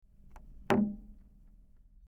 Wooden Plank Placed Down | TLIU Studios
Category: Object Mood: Subtle Editor's Choice